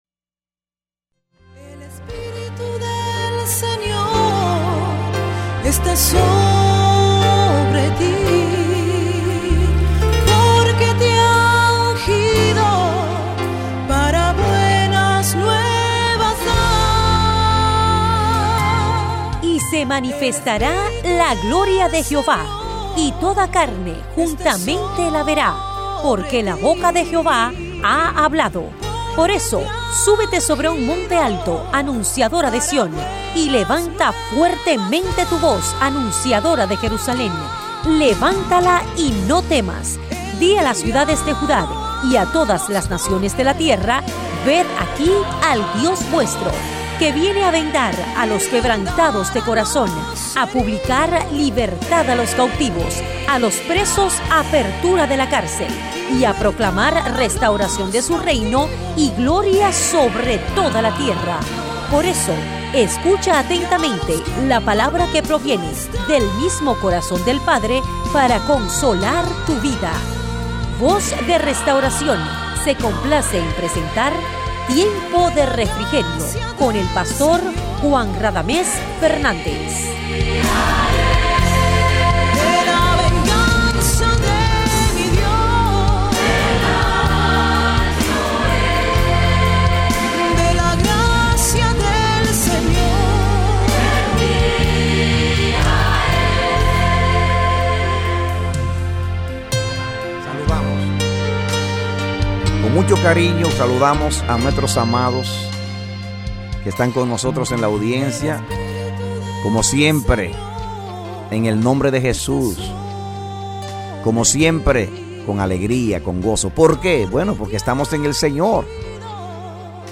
A mensaje from the serie "Programas Radiales."